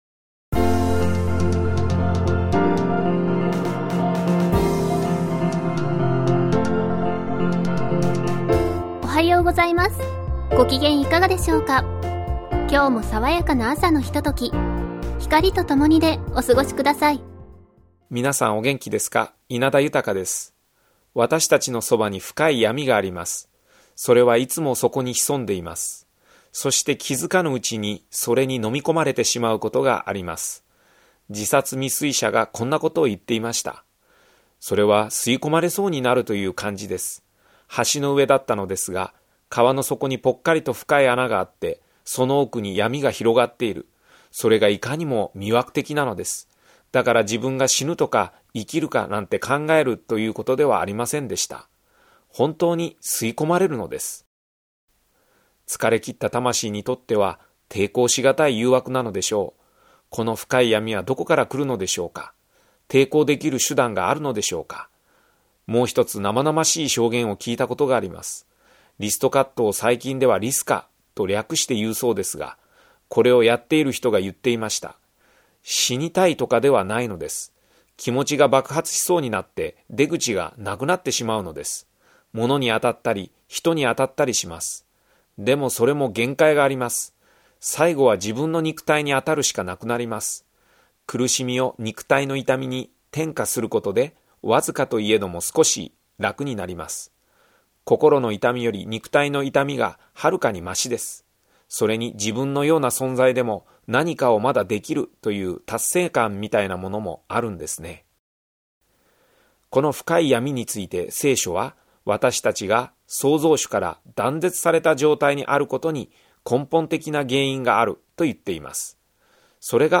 ラジオNIKKEIで放送中のキリスト教番組です。